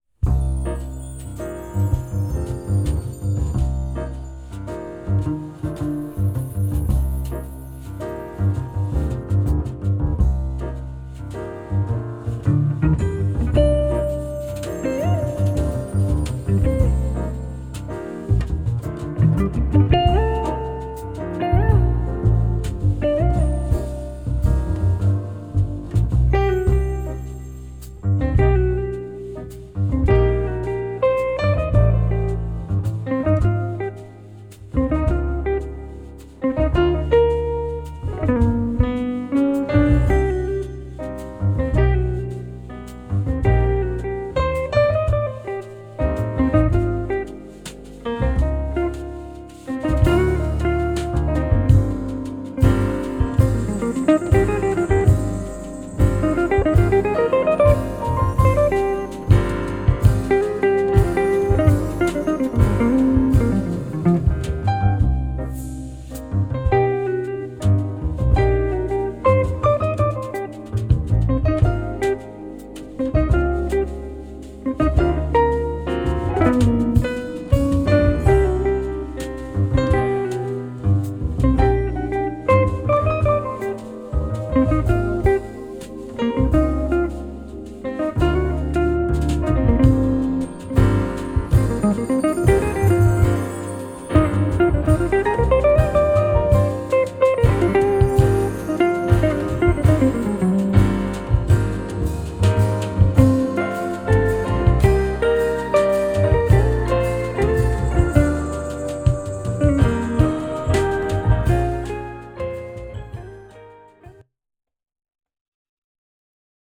Моя октава с FIN схемой. Расстояние до кубика примерно 8-10 см. "Orig" оригинальный файл моно, "1 no sub" запись с микрофона.